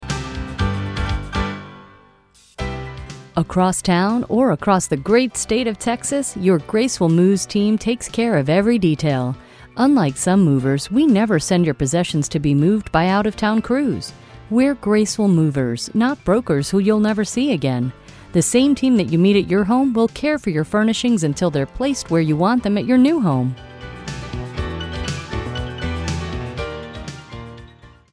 Your telephone message educates your caller with interesting phone advertisements delivered by a professional voice over.
Here are actual samples of Informer Messages for two of our Moving and Storage clients